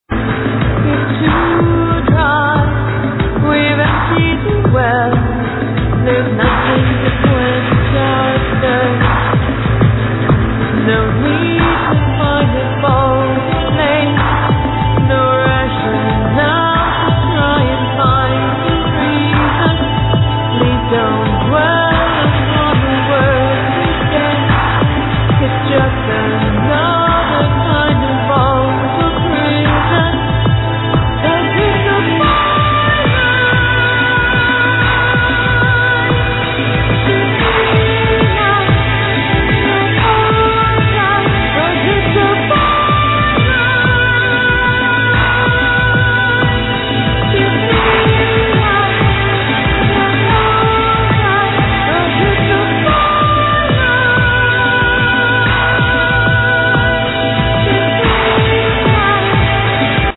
Vocals
Violin
Guitars, Bass, Programming
Drums